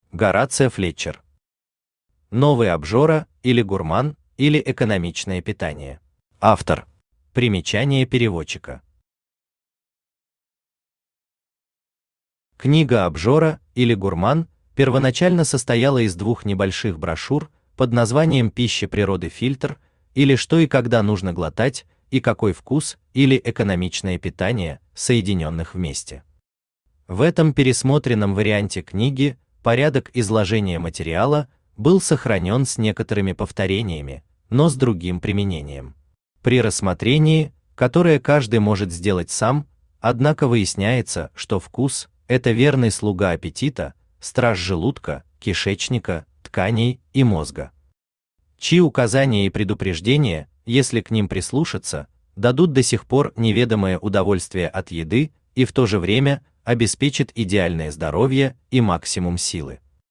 Aудиокнига Новый обжора или гурман, или Экономичное питание Автор Горацио Флетчер Читает аудиокнигу Авточтец ЛитРес.